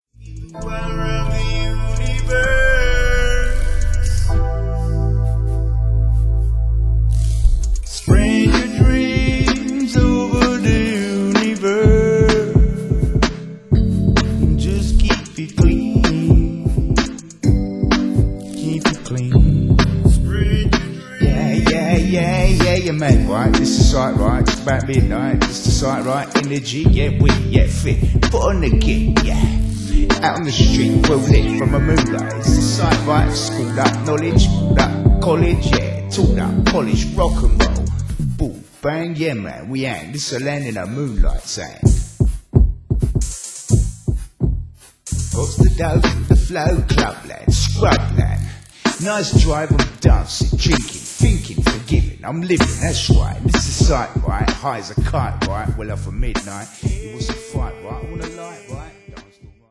Ambient - Electro - Jazz